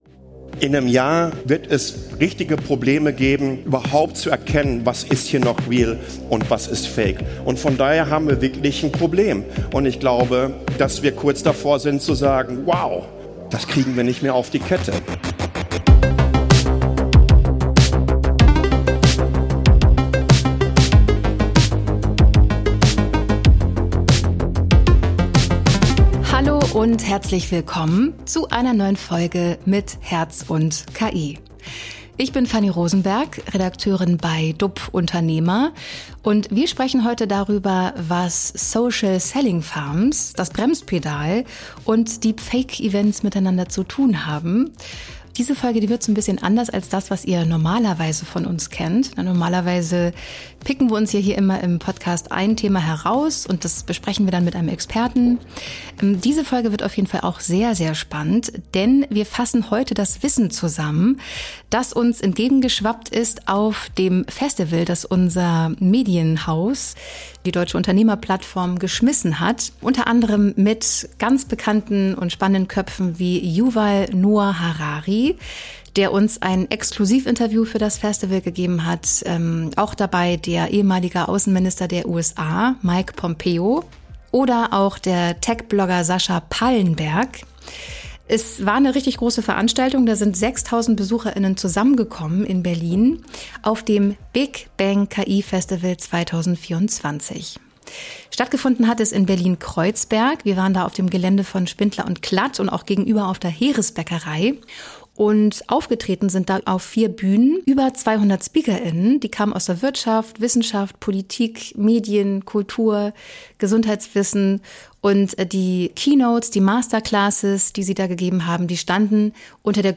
Auf unserem mit über 6000 Besucher*innen ausverkauften BIG BANG KI FESTIVAL, das wir als DUP-Unternehmer im September 2024 in Berlin geschmissen haben, sind mehr als 200 Speaker*innen zusammengekommen, um die neuesten Entwicklungen, Chancen und Risiken rund um KI-Technologien aufzuzeigen.
Ein herausragender Moment war das Exklusiv-Interview mit Historiker und Bestseller-Autor Yuval Noah Harari, der erklärt, warum KI-Technologien die gefährlichste Erfindung der Menschheitsgeschichte sind, und davor warnt, bei KI aufs Gaspedal zu drücken, bevor wir wissen, wo die Bremse ist.